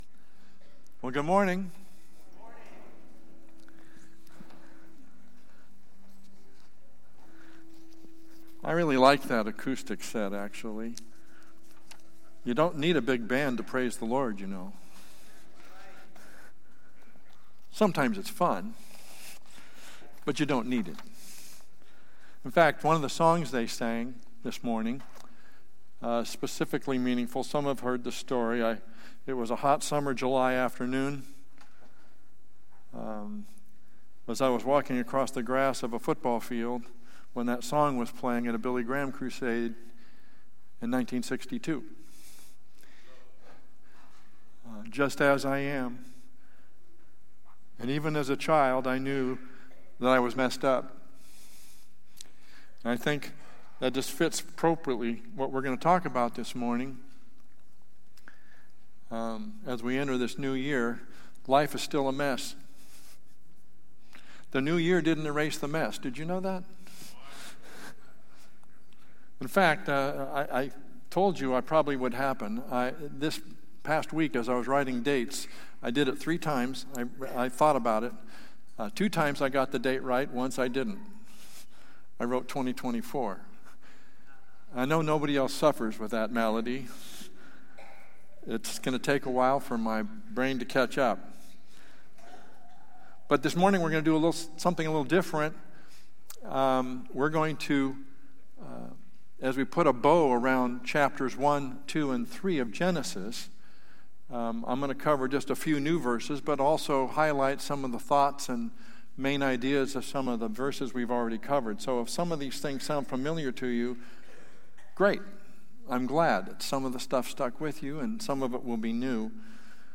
Valley Bible Church Sermons (VBC) - Hercules, CA